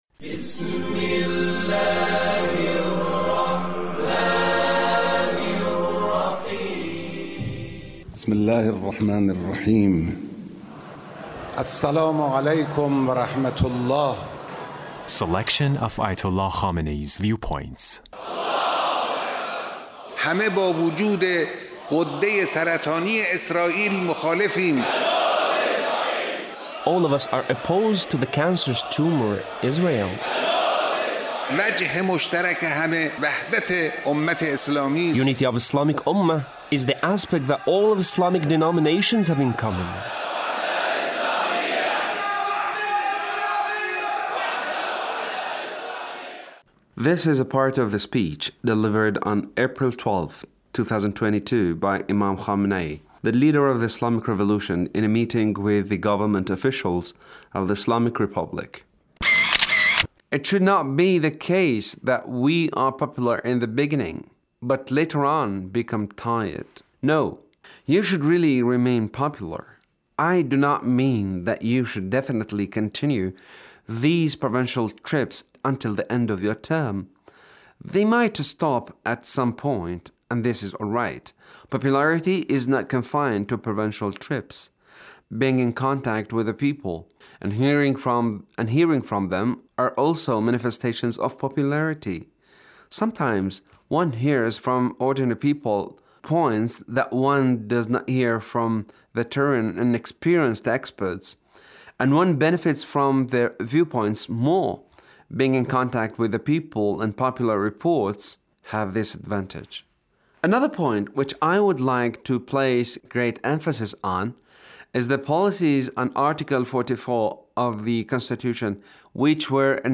The Leader's speech in a meeting with Government Officials